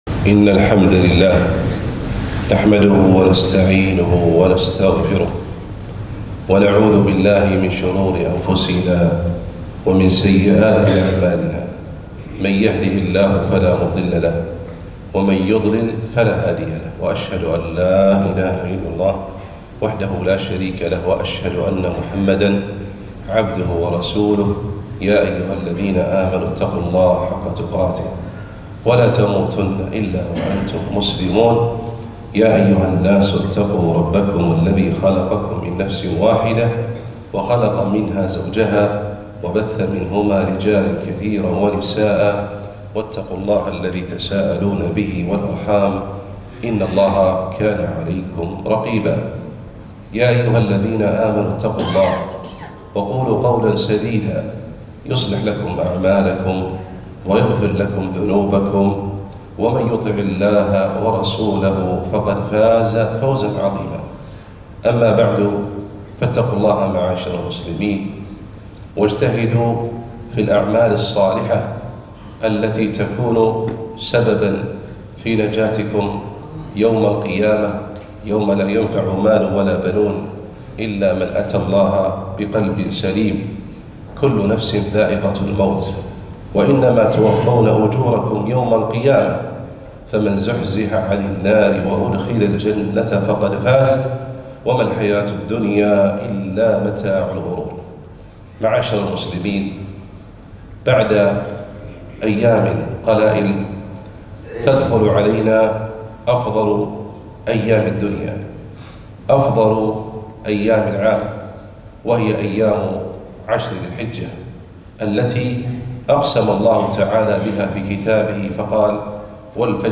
خطبة - وأقبلت خير أيام الدنيا